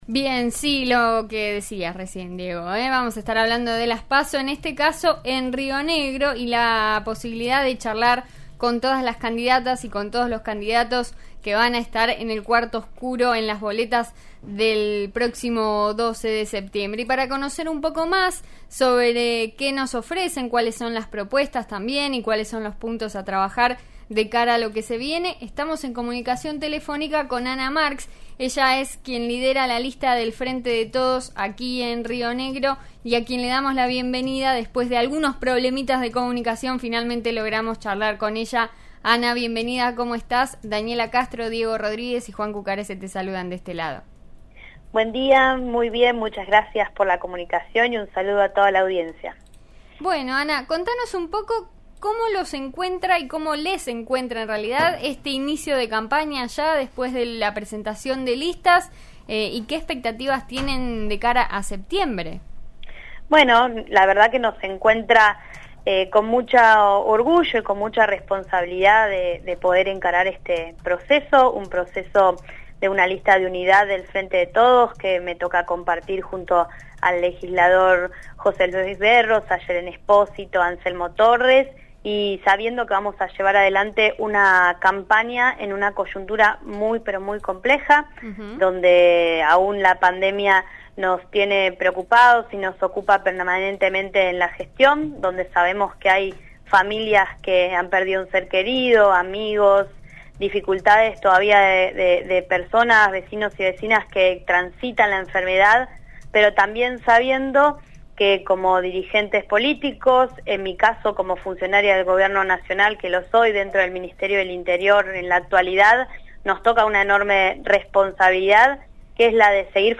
'En eso estamos' de RN Radio dialogó con Ana Marks, quien lidera la lista del Frente de Todos en Río Negro, respecto a las PASO.